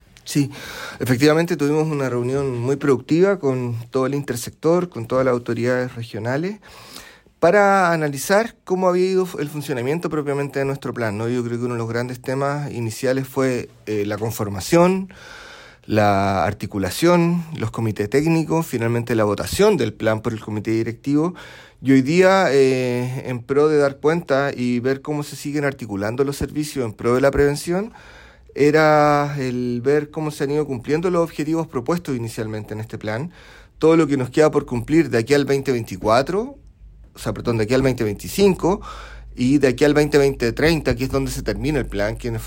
Rodrigo-Maturana-Director-Regional-de-SENDA-online-audio-converter.com_.mp3